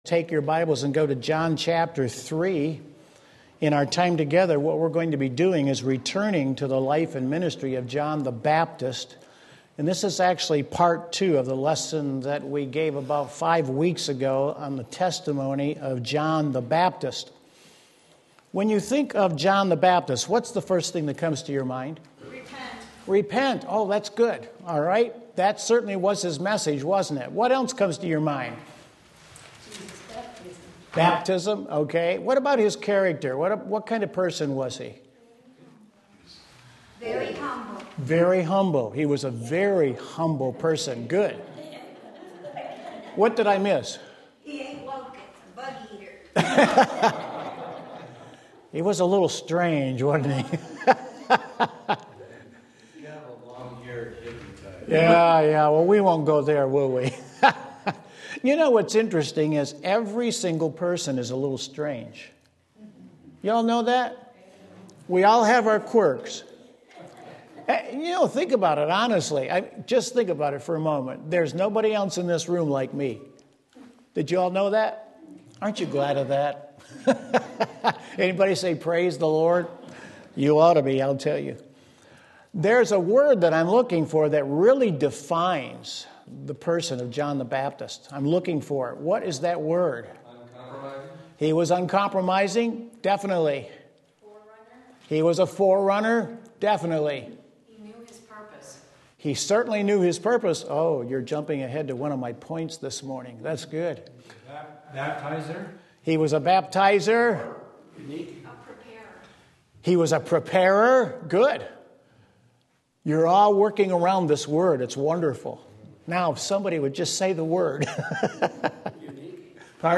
Sermon Link
Part 2 John 3:22-36 Sunday School